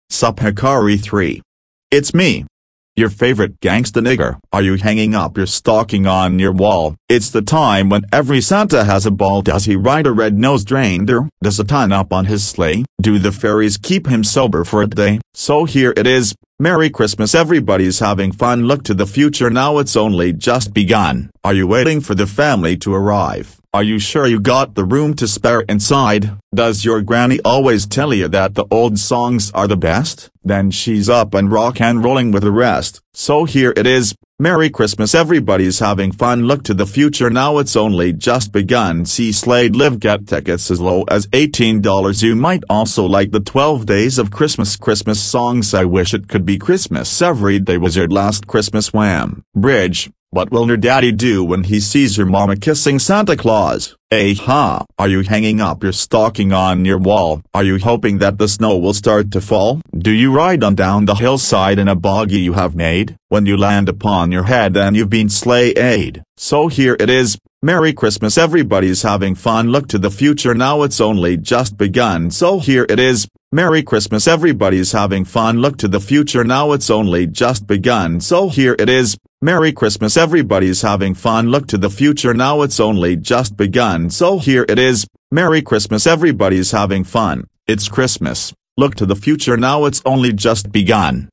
Took me a while because my voice is a little hoarse